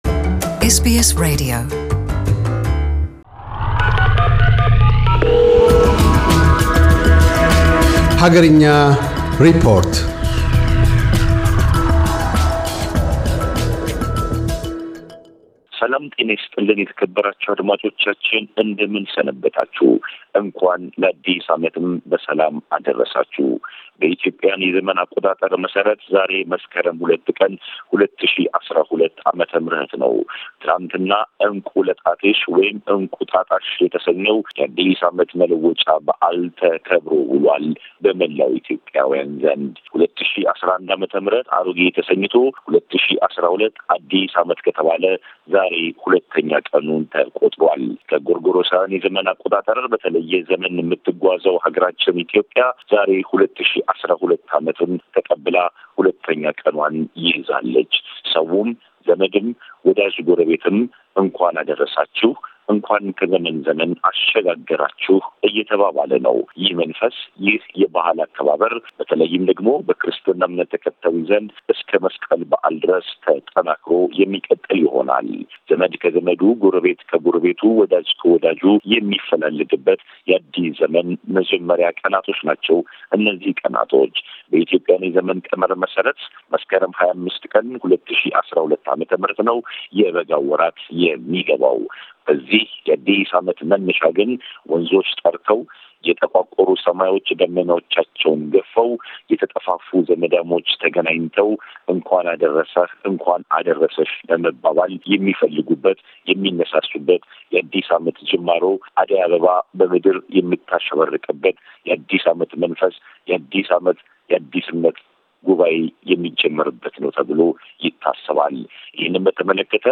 አገርኛ ሪፖርት - የኢትዮጵያን የዘመን ቅመራ ታሪክ፣ የ2011 ሽኝትና 2012 የአገር ቤት ቅበላን፣ የመሪዎችና የሃይማኖት አባቶችን መልዕክቶች አካትቶ ያንሳል።